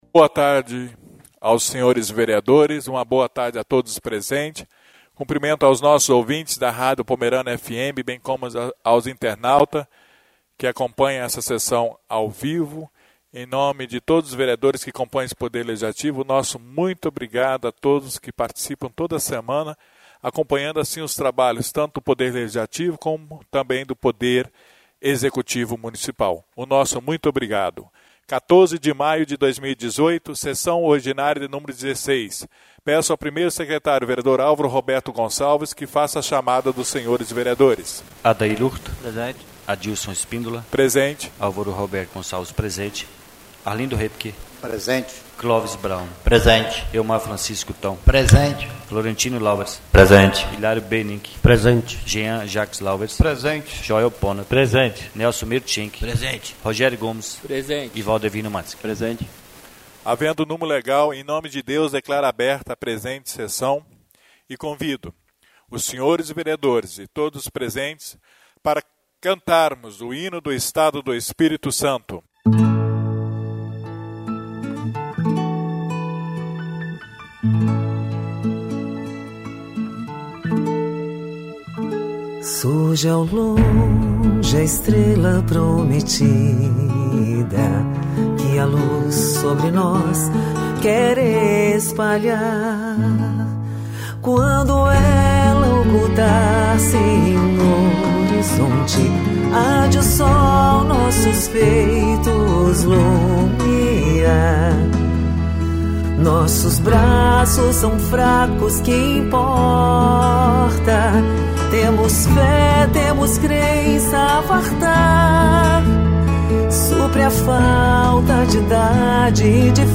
16ª Reunião Ordinária da 2ª Sessão Legislativa da 8ª Legislatura 14 de Maio de 2018